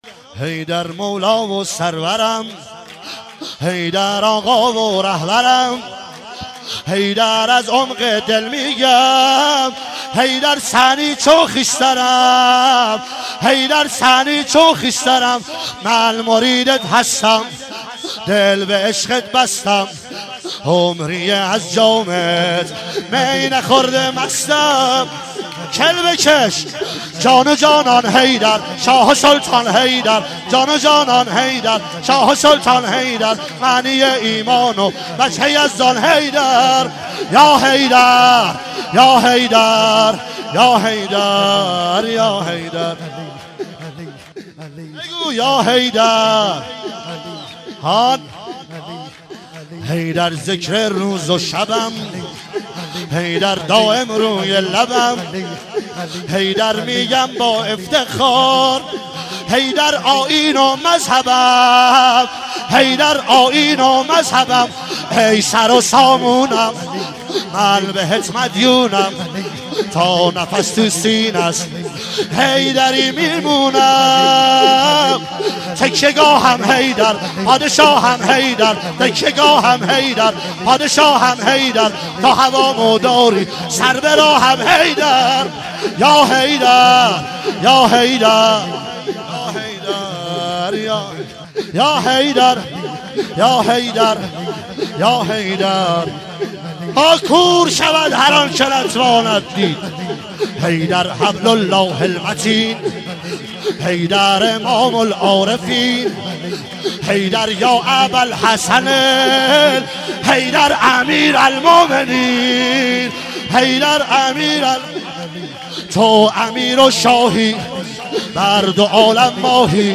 عنوان عید غدیر ۱۳۹۸
مداح
شور